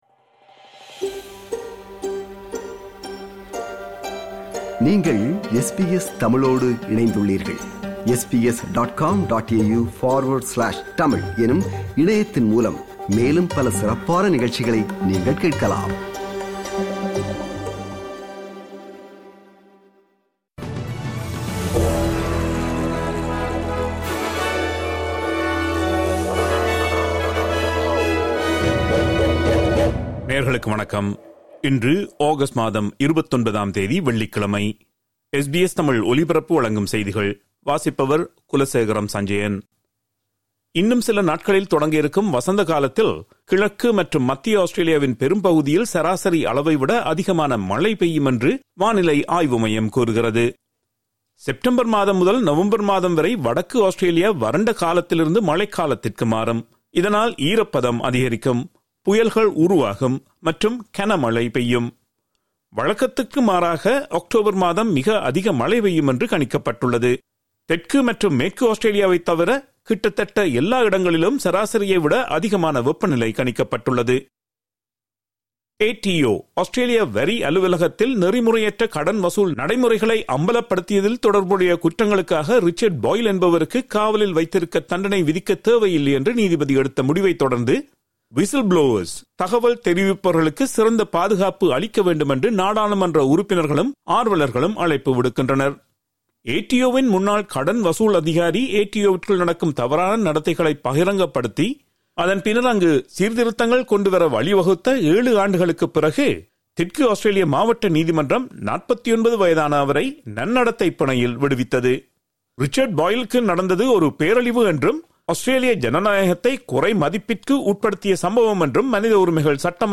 SBS தமிழ் ஒலிபரப்பின் இன்றைய (வெள்ளிக்கிழமை 29/08/2025) செய்திகள்.